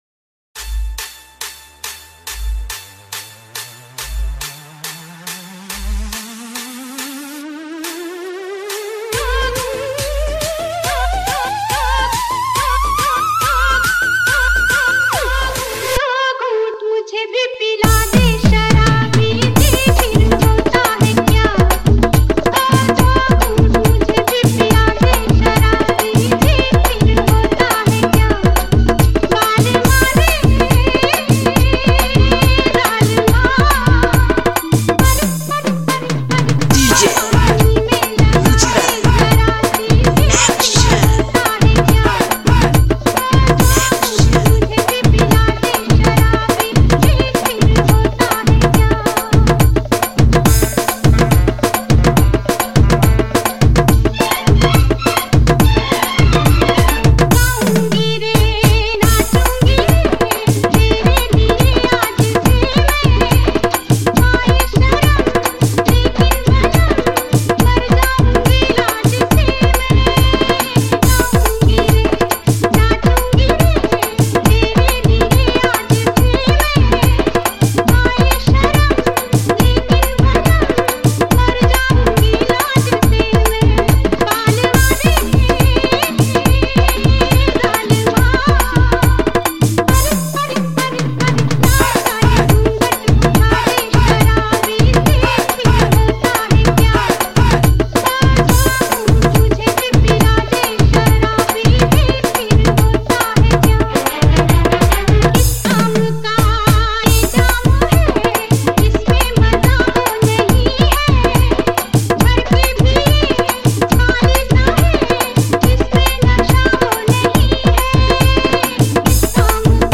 High quality Sri Lankan remix MP3 (3.8).
remix